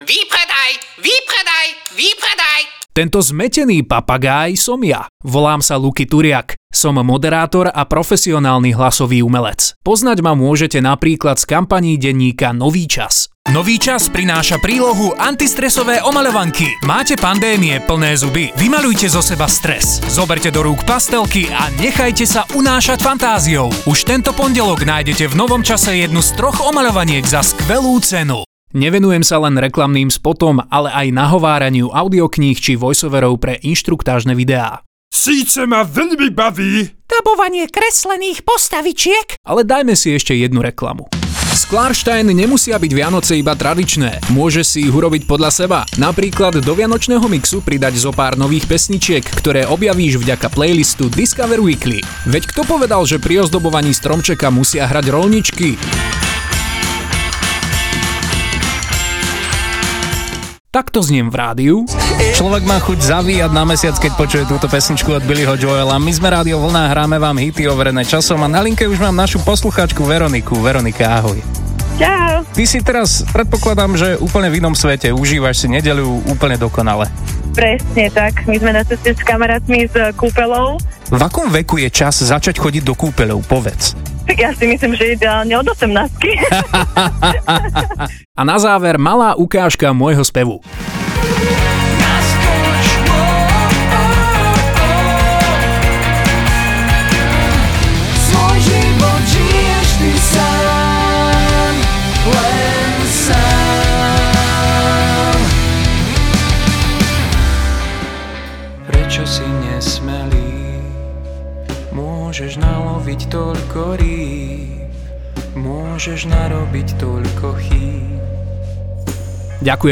To všetko s možnosťou nahrávania vo vlastnom štúdiu. Takto zniem v rôznych polohách: